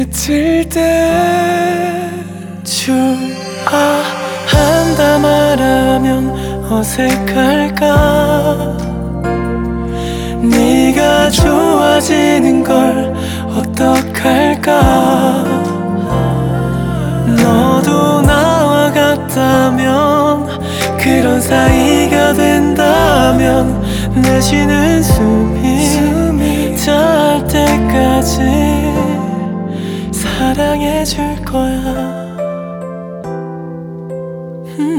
Плавные вокальные партии и чувственный бит
Глубокий соул-вокал и плотные хоровые партии
K-Pop Pop R B Soul
Жанр: Поп музыка / R&B / Соул